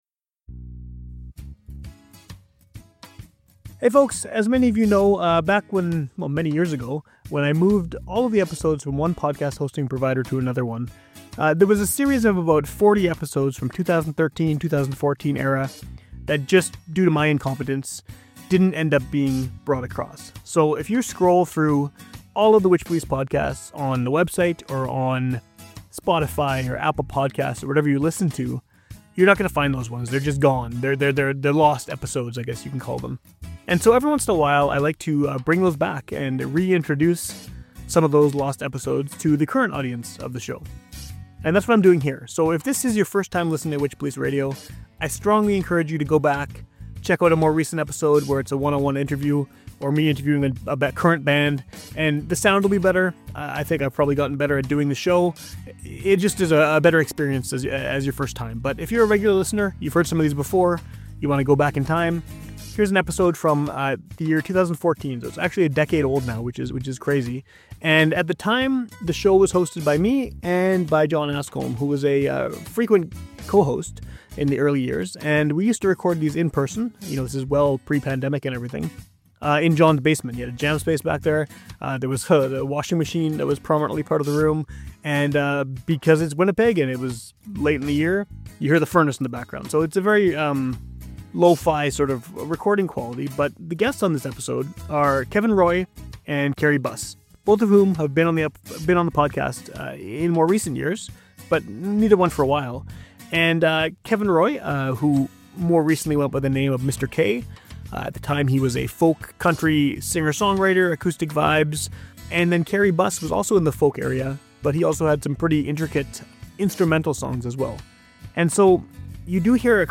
This one is particularly notable because it has absolutely deadly live performances by each of the guests.